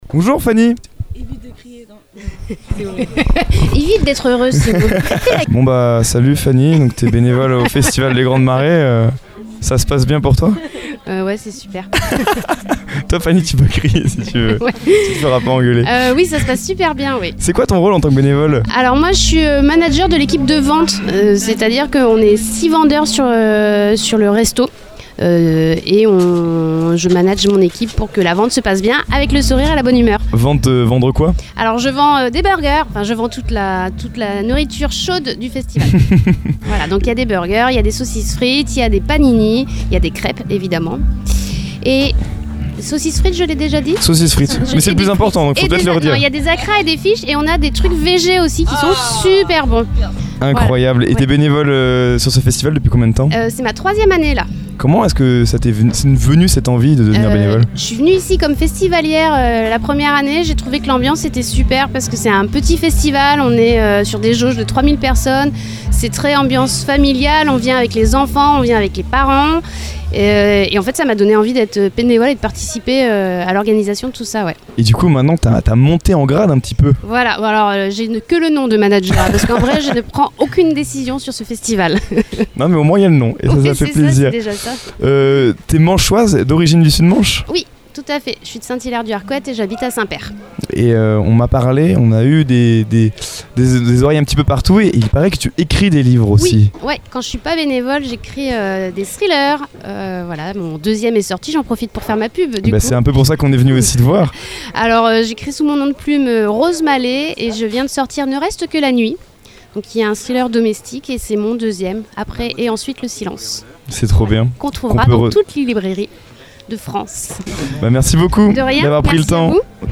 nous faisons escale au Les Grandes Marées
Une interview chaleureuse et authentique qui met en lumière celles et ceux qui œuvrent dans l’ombre pour faire vivre l’esprit convivial et festif du festival Les Grandes Marées.